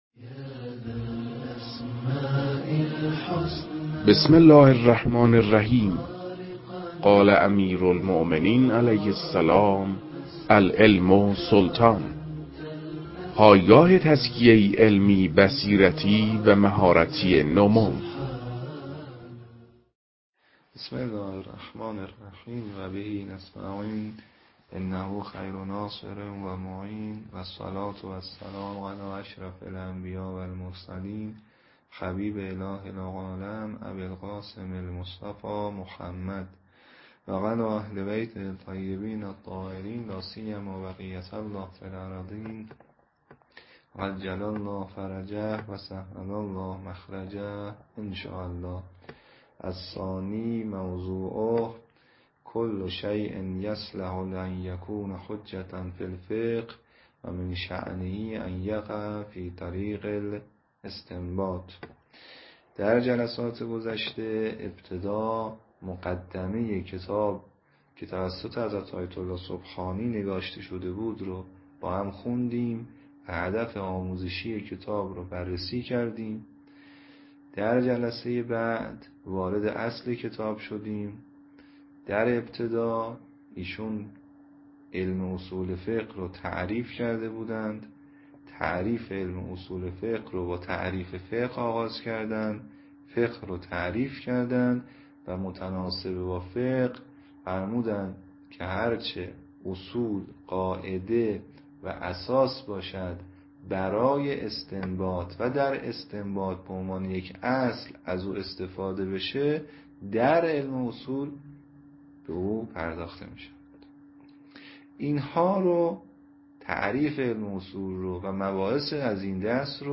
در این بخش، کتاب «الموجز» که اولین کتاب در مرحلۀ آشنایی با علم اصول فقه است، به صورت ترتیب مباحث کتاب، تدریس می‌شود.